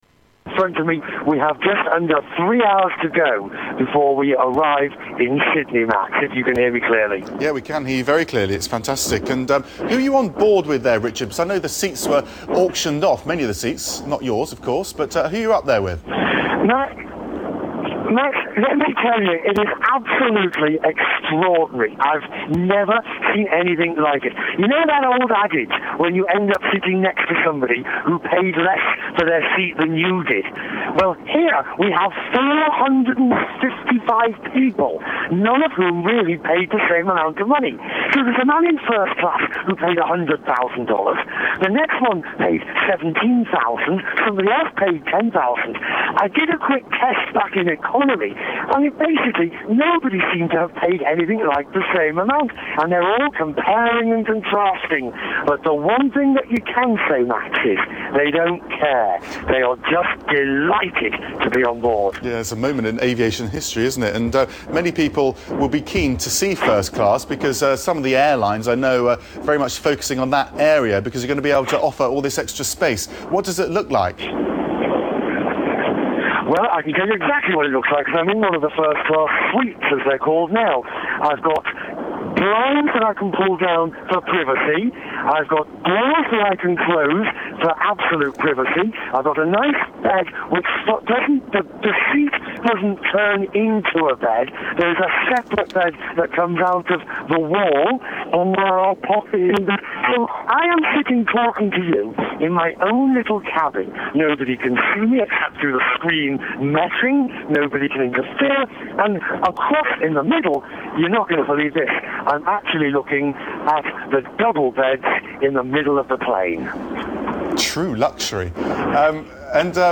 Tags: Media Richard Quest News Reporter Business Traveller Richard Quest Audio clips